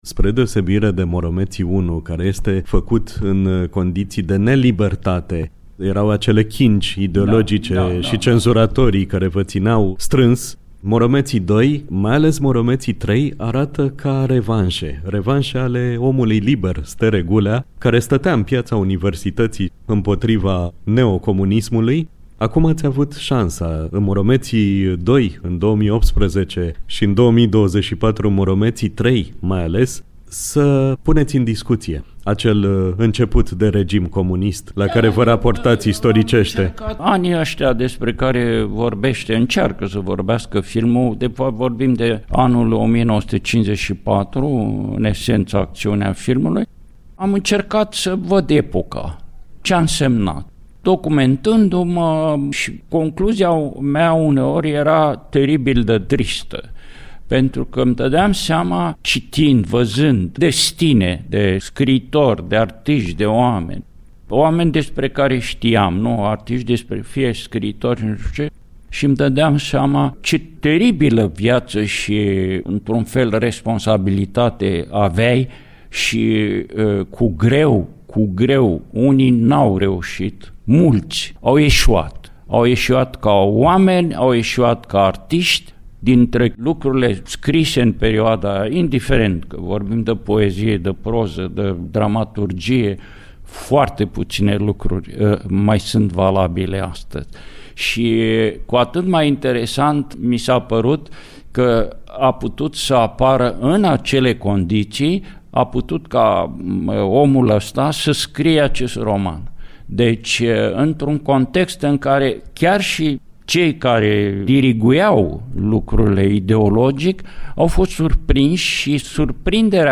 Maestrul Stere Gulea la microfonul Radio România Iași